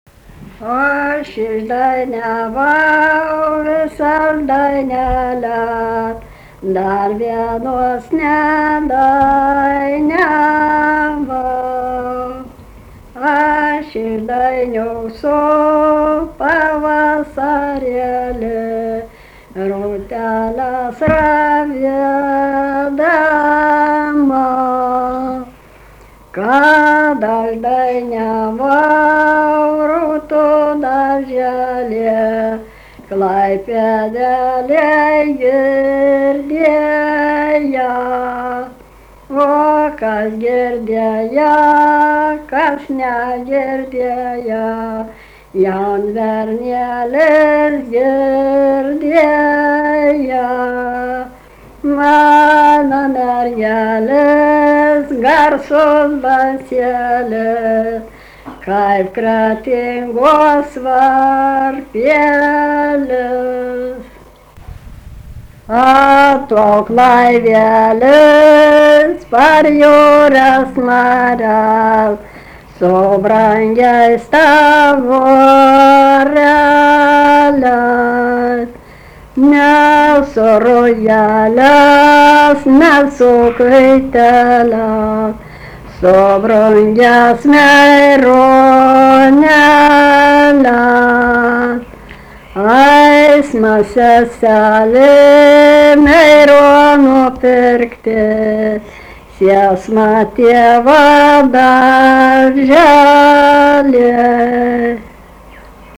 daina, vestuvių
Erdvinė aprėptis Ryžiškė Gudžionys Rapaliai
Atlikimo pubūdis vokalinis
Pastabos 2 balsai